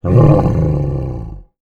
MONSTER_Growl_Medium_25_mono.wav